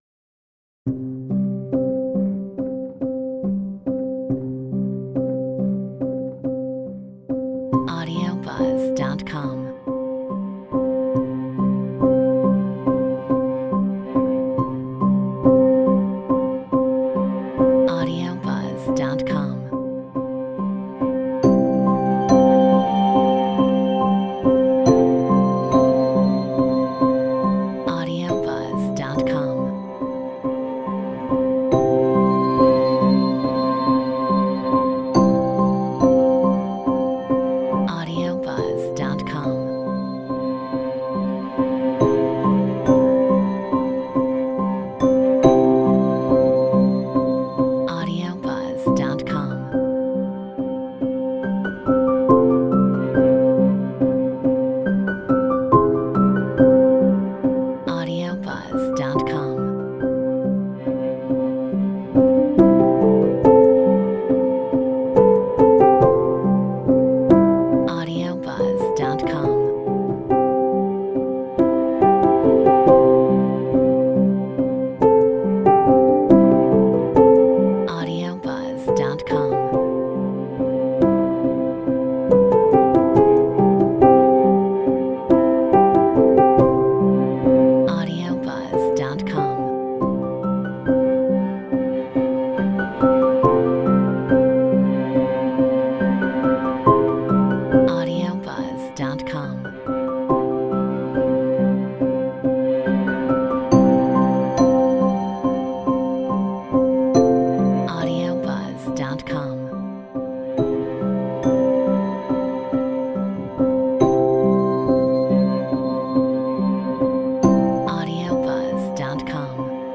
Metronome 70 BPM
Piano / Solo Instrumental Production / Film Scores
Bells Cello Musicbox Piano Synthesizer Textures